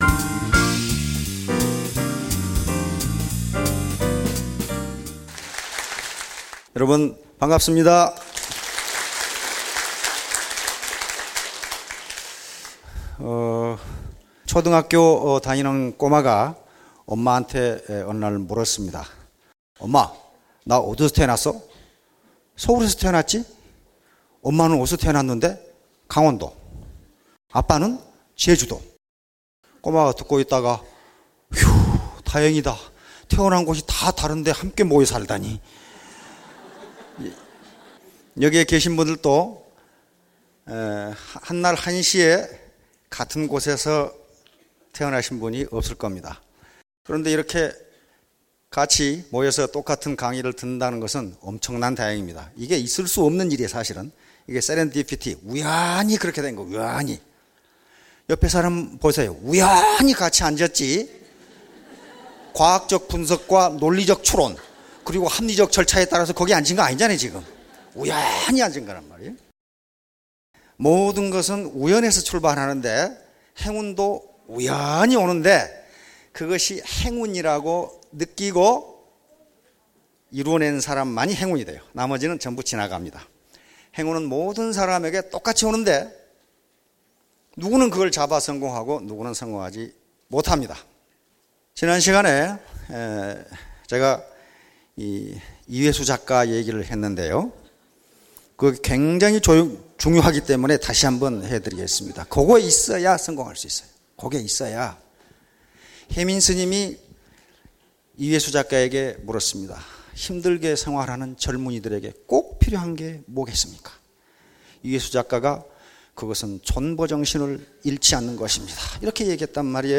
장소 : SBT 원데이 세미나